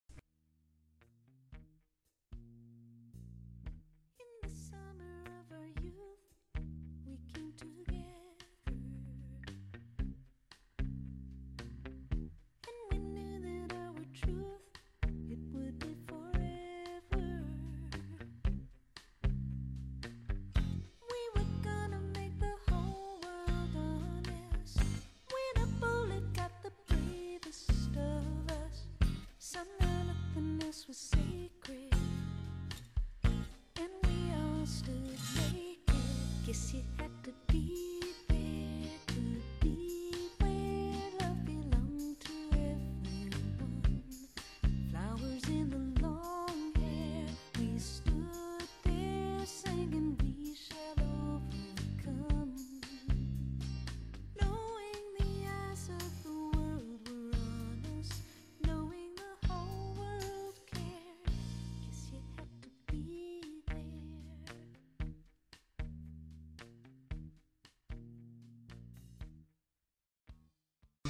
【所屬類別】 CD唱片　　爵士及藍調